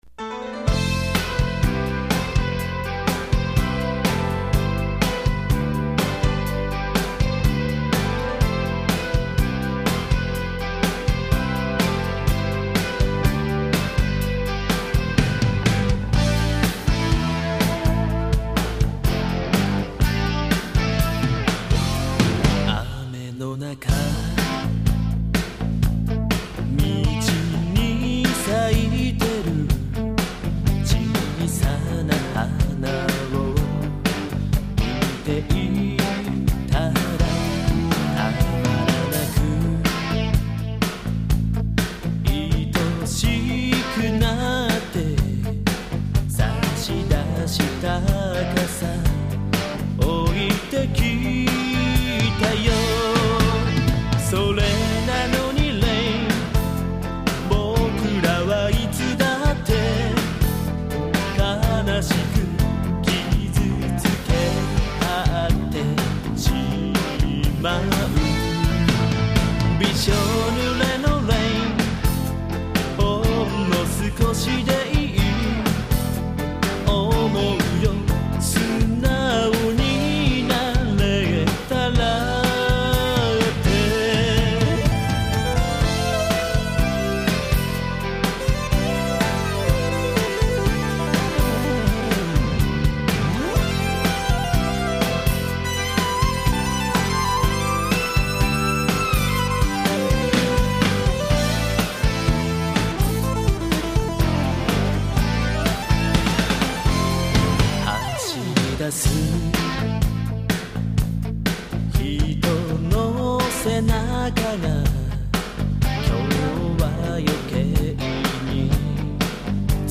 keyboard
guitar